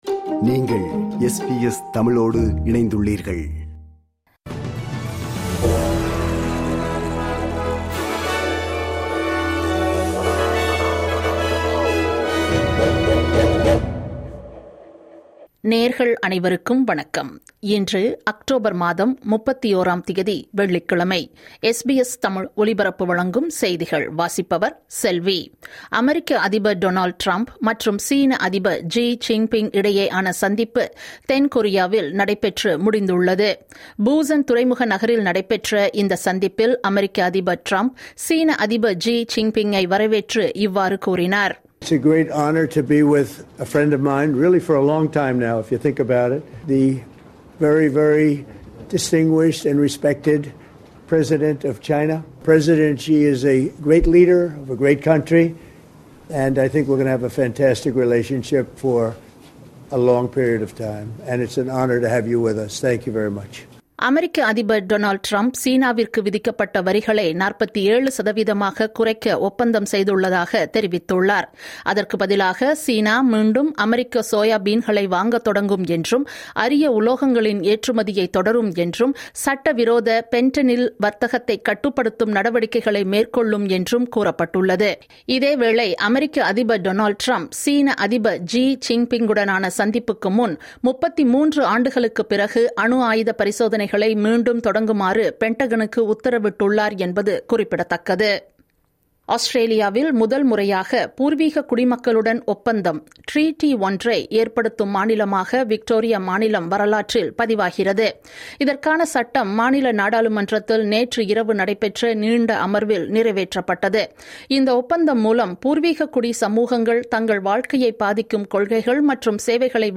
இன்றைய செய்திகள்: 31 அக்டோபர் 2025 - வெள்ளிக்கிழமை
SBS தமிழ் ஒலிபரப்பின் இன்றைய (வெள்ளிக்கிழமை 31/10/2025) செய்திகள்.